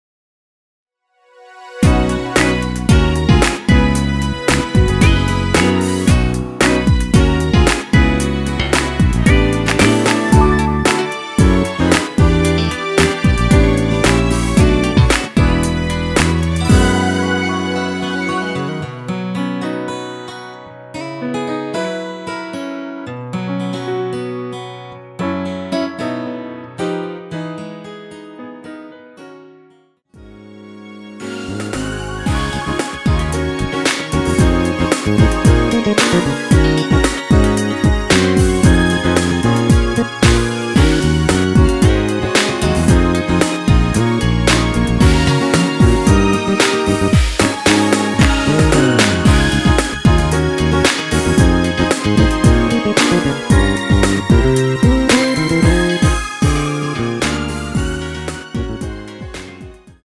원키에서(+3)올린 MR입니다.(미리듣기 참조)
앞부분30초, 뒷부분30초씩 편집해서 올려 드리고 있습니다.
중간에 음이 끈어지고 다시 나오는 이유는